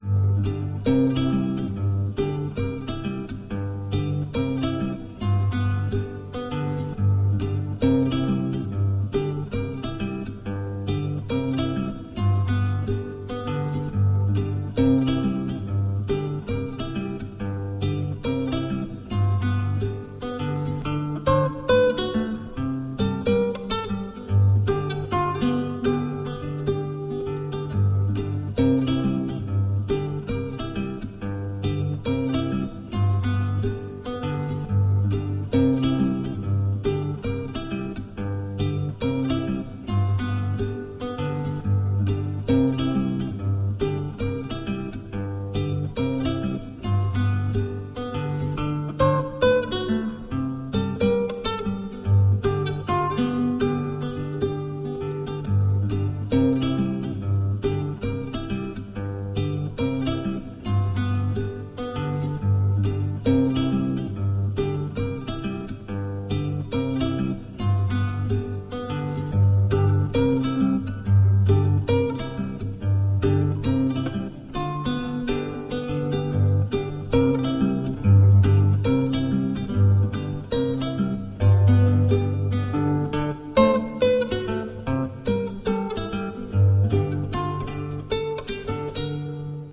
描述：三角洲蓝调
Tag: 吉他 原声